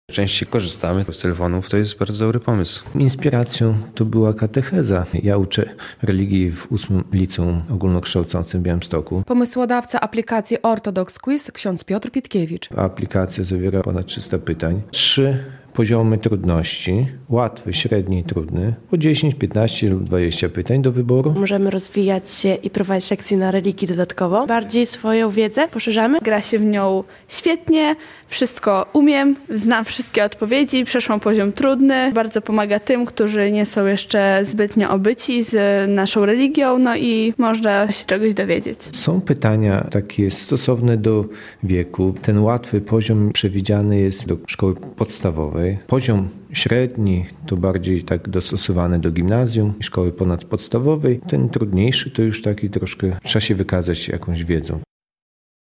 relacja
"Taka aplikacja to dobry pomysł i często z niej korzystamy" – podkreślają uczniowie.